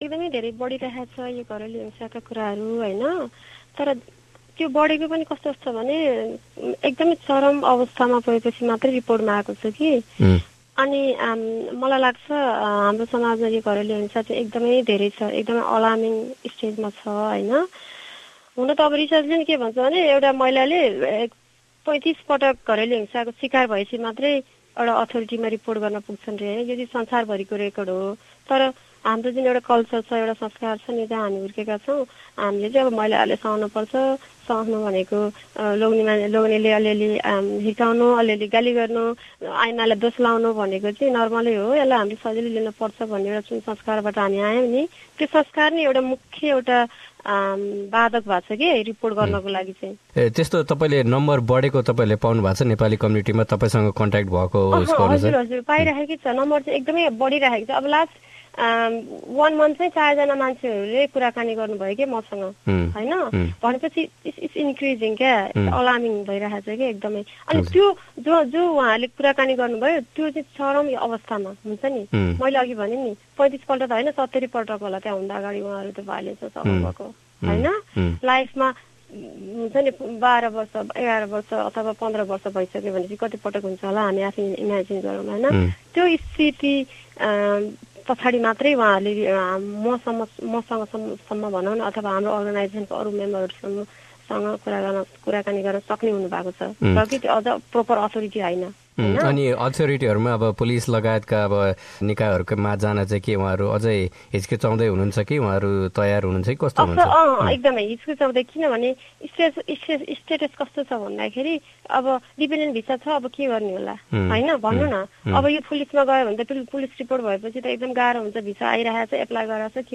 एसबीएस नेपालीसँग गरेको कुराकानी।